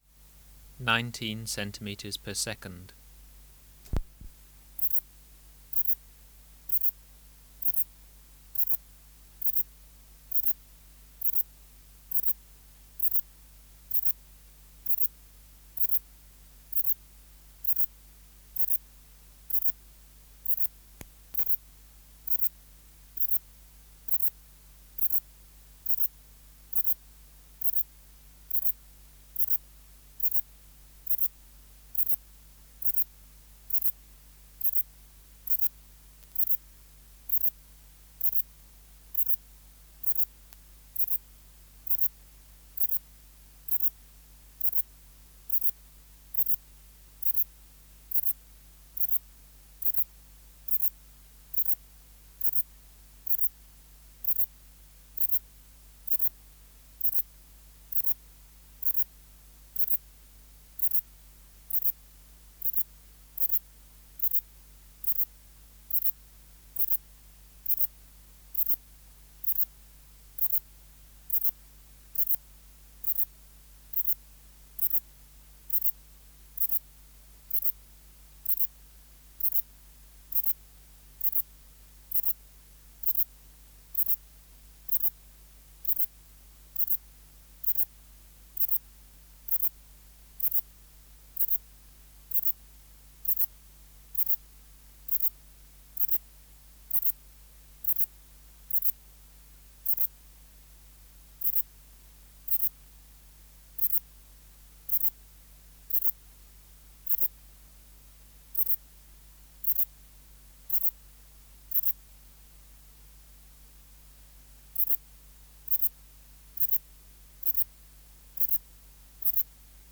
576:2 Hybrid Ephippiger cunii & E.cruciger (230b) | BioAcoustica
Species: Ephippiger cunii x cruciger
Recording Location: BMNH Acoustic Laboratory
Reference Signal: 1 kHZ for 10s
Substrate/Cage: Recording cage
Microphone & Power Supply: Sennheiser MKH 405 Distance from Subject (cm): 30